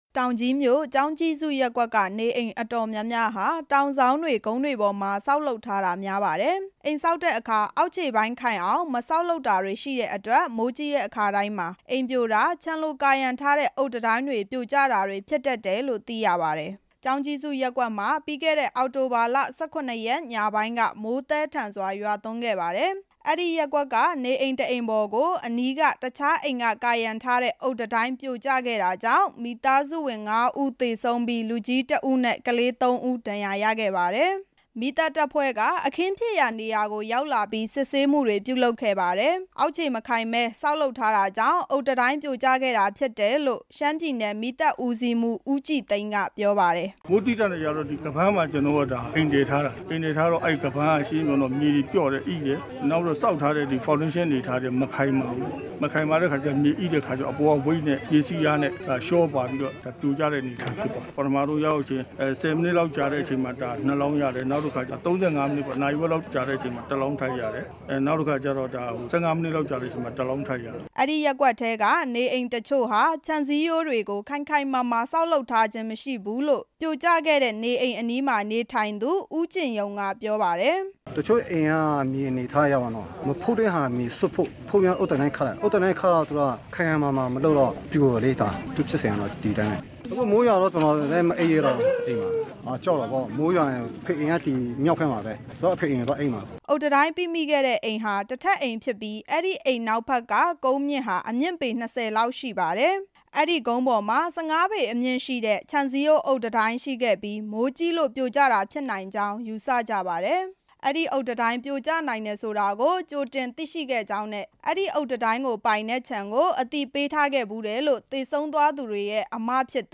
မိဘတွေသေဆုံးသွားတဲ့အပြင် ဒဏ်ရာရခဲ့တဲ့ ကျောင်း သားကျောင်းသူ ၃ ဦးရဲ့ဘဝရပ်တည်နိုင်ရေးအတွက် ရှမ်းပြည်နယ် အစိုးရအဖွဲ့က ကူညီပေးသွားမယ် လို့ ပြည်နယ်ဘဏ္ဍာရေးနဲ့ အခွန်ဝန်ကြီး ဦးခွန်သိန်းမောင်က ပြောပါတယ်။